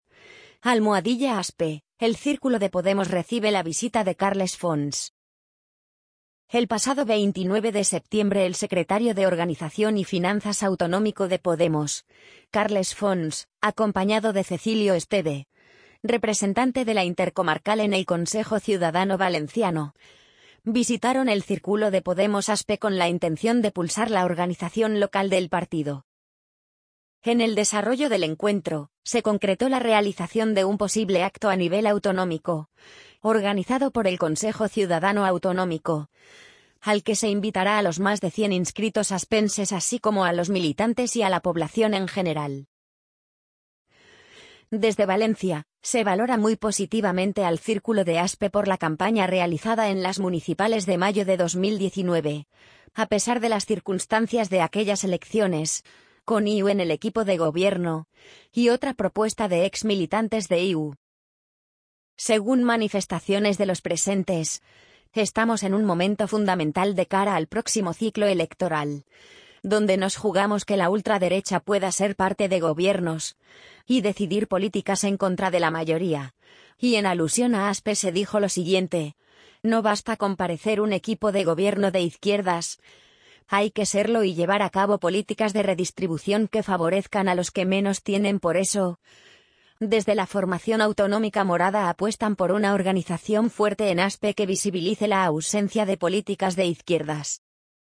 amazon_polly_60653.mp3